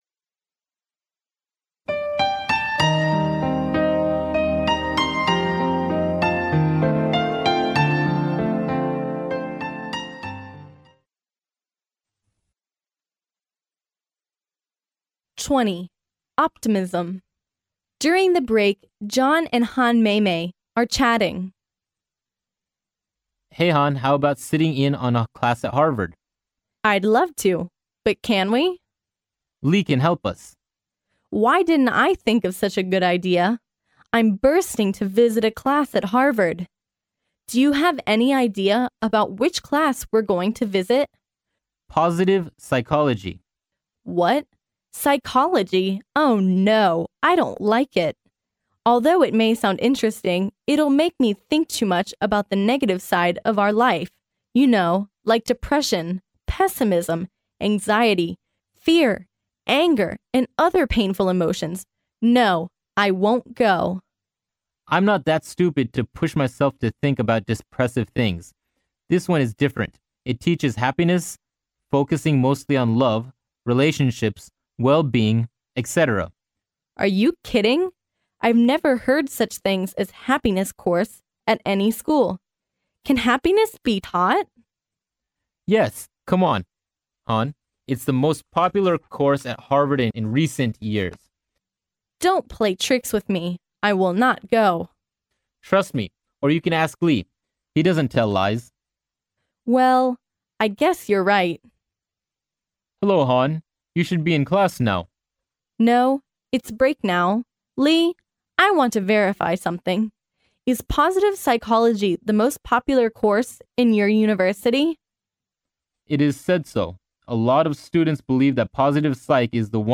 哈佛大学校园英语情景对话20：乐观主义（mp3+中英）